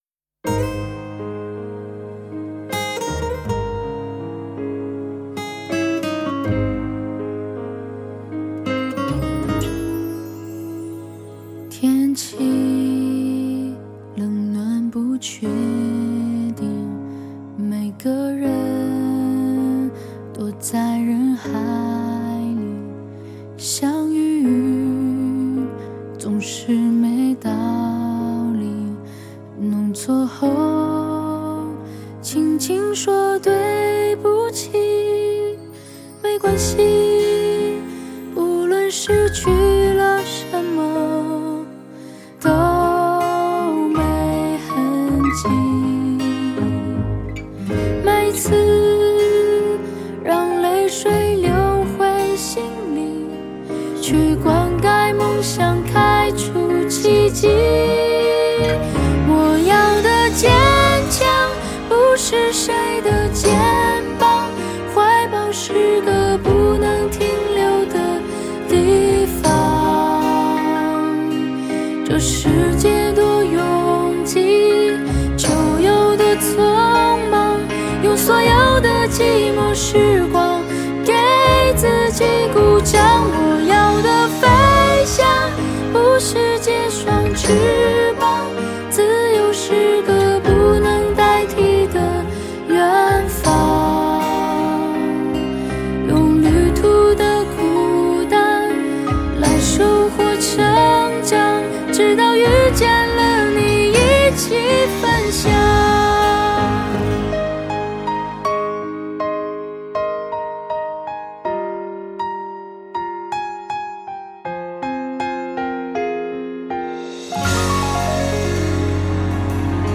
Ps：在线试听为压缩音质节选，体验无损音质请下载完整版 作曲